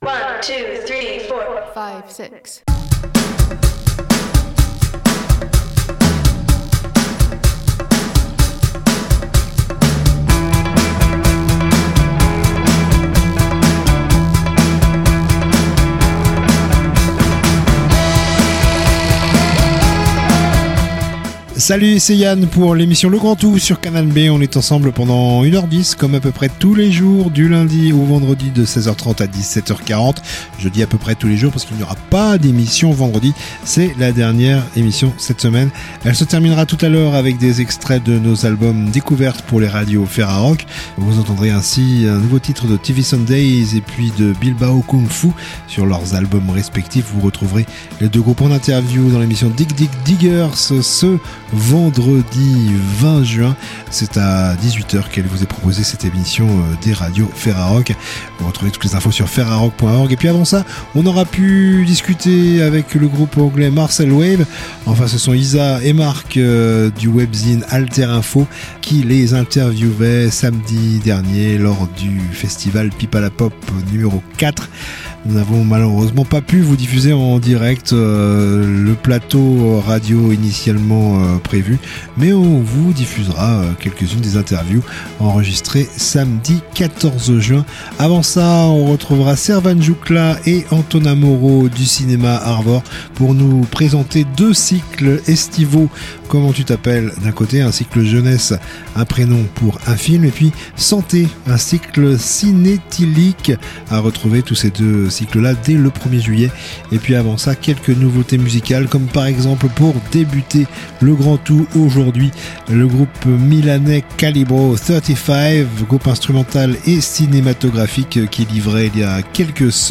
Discussion enregistrée le 14/06 au Festival Pies Pala Pop # 4.